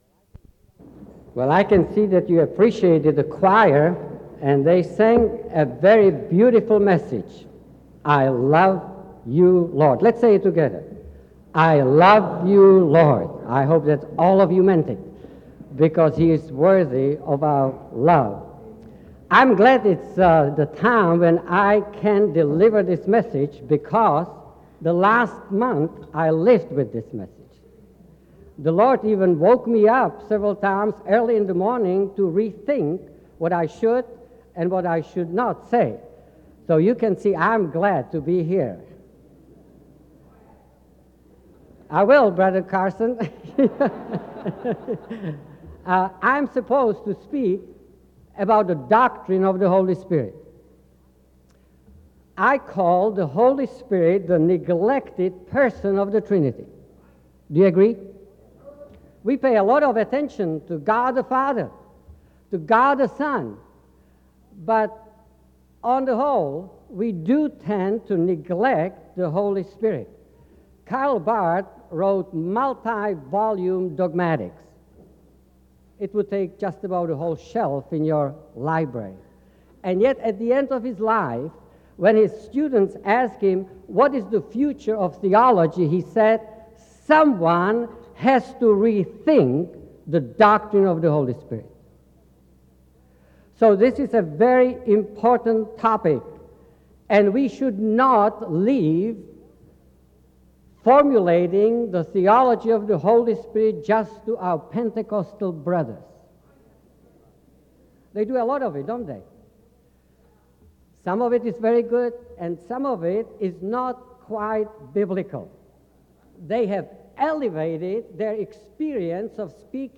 SEBTS Chapel and Special Event Recordings